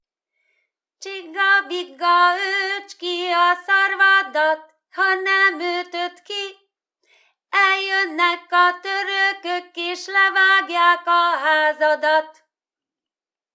TípusI. Népi játékok / 18. Állatmondókák
TelepülésNádszeg [Trstice]